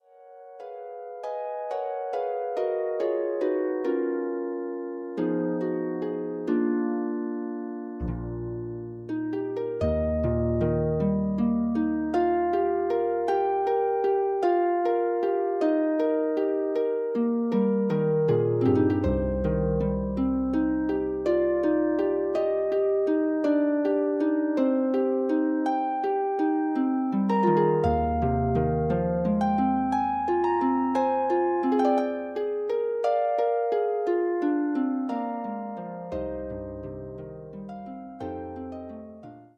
arranged for solo pedal harp.